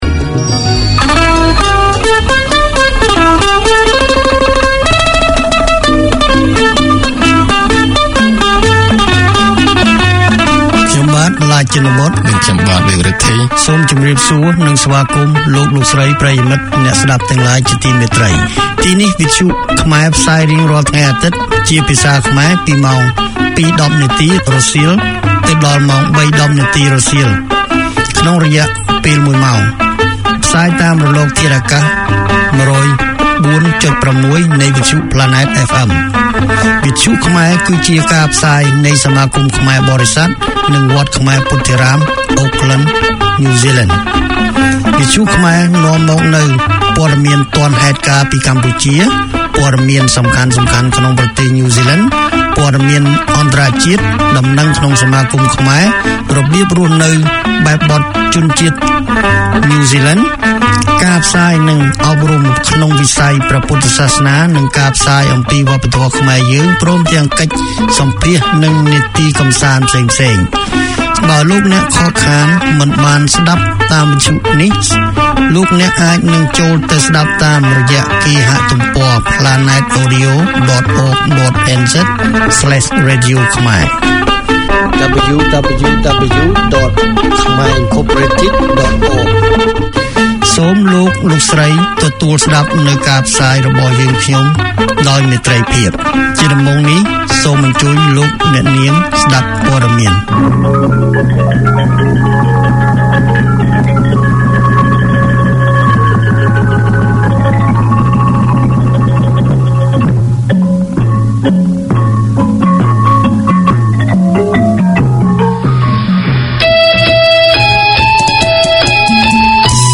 Brought to you by Khmer Inc., Khmer community radio provides service to the Khmer people with Khmer news, news in NZ, community notices, orientation for Cambodian migrants to the Kiwi way, Buddhist Dharma teaching, Khmer cultural topics, guest interviews and talkback.